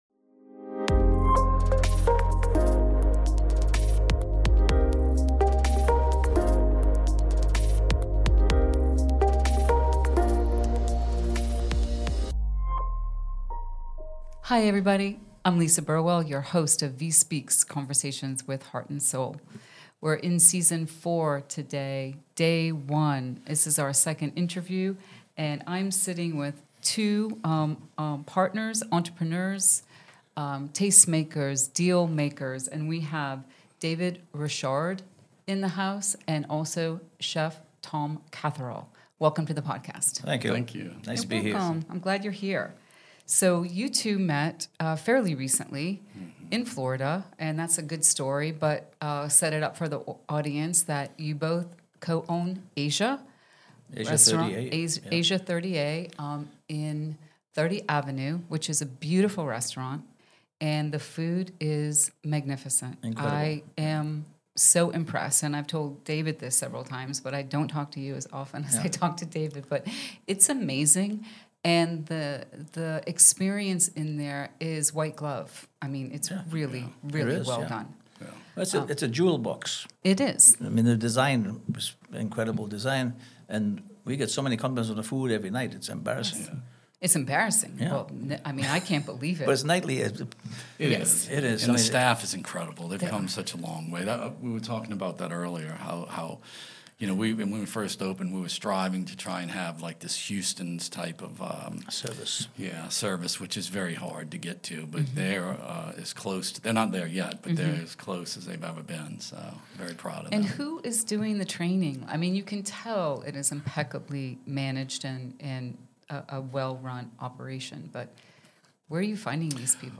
Join us for an insightful conversation in our latest episode of "VIE Speaks